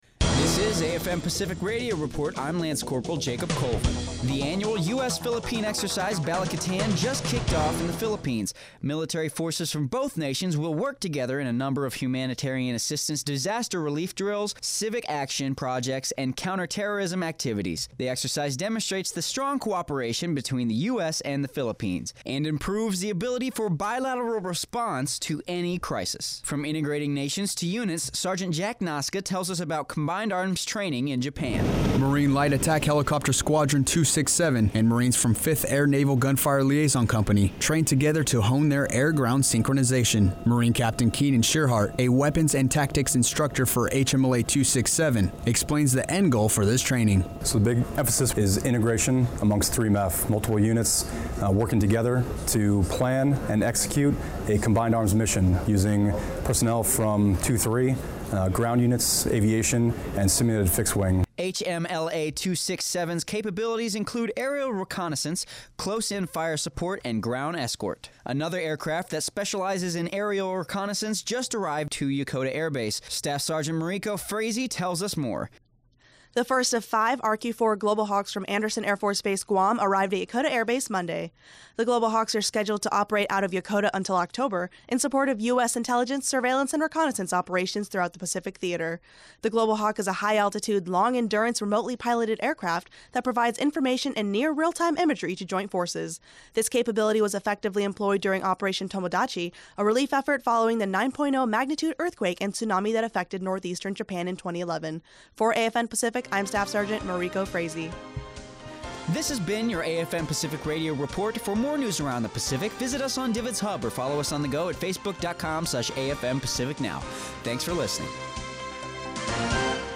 Pacific Radio Report